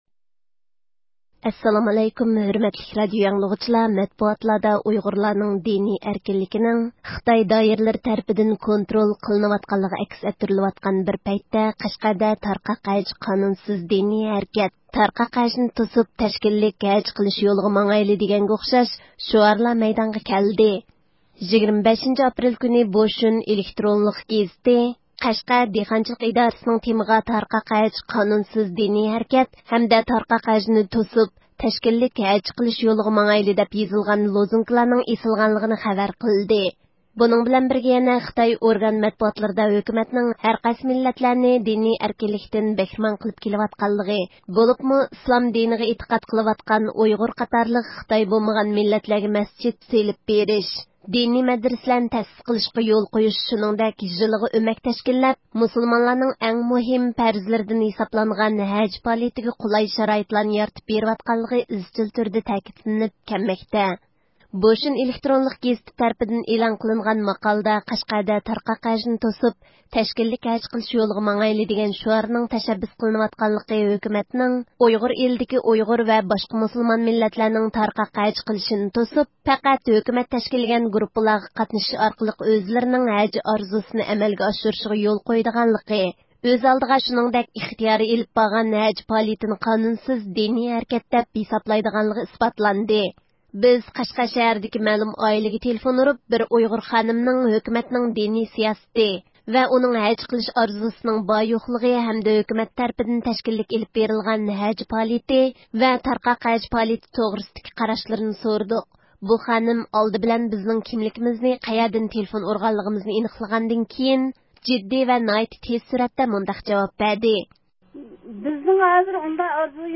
گېزىتلەرنىڭ ئىنكاسى
بىز قەشقەر شەھىرىدىكى مەلۇم ئائىلىگە تېلېفۇن ئۇرۇپ، بىر ئۇيغۇر خانىمنىڭ، ھۆكۈمەتنىڭ دىنىي سىياسىتى ۋە ئۇنىڭ ھەج قىلىش ئارزۇسىنىڭ بار -يوقلۇقى ھەمدە ھۆكۈمەت تەرىپىدىن تەشكىللىك ئېلىپ بېرىلغان ھەج پائالىيىتى ۋە تارقاق ھەج پائالىيىتى توغرىسىدىكى قاراشلىرىنى سورىدۇق. بۇ خانىم، ئالدى بىلەن بىزنىڭ كىملىكىمىزنى، قەيەردىن تېلېفۇن ئۇرغانلىقىمىزنى ئېنىقلىغاندىن كېيىن، جىددىي ۋە ناھايىتى تېز سۈرئەتتە سۇئالىمىزغا جاۋاب بەردى.